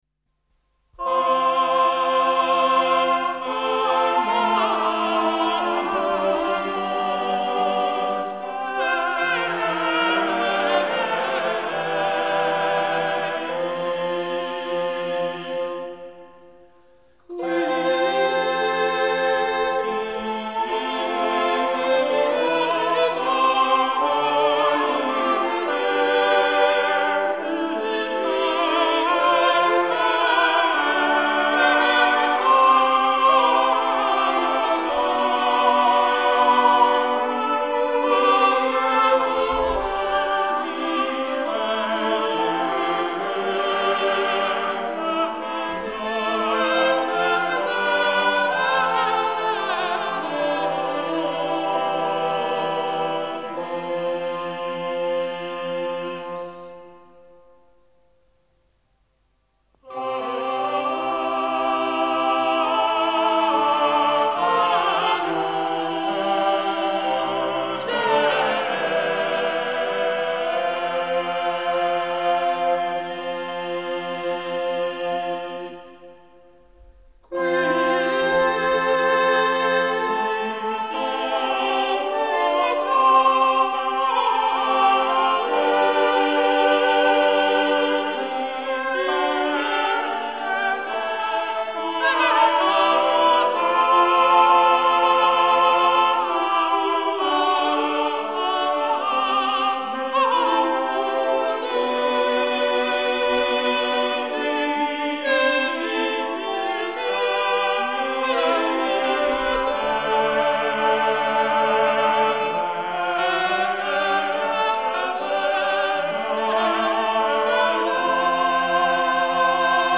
Scritta alla metà del secolo (tra il 1349 e il 1363) la Messa di Notre Dame si distingue dalle altre messe contemporanee per l'organico a 4 voci e per il trattamento isoritmico della melodia gregoriana nel Kyrie, Sanctus, Agnus e Ite missa est.
Deller consort, London | © 1961 | info